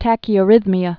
(tăkē-ə-rĭthmē-ə)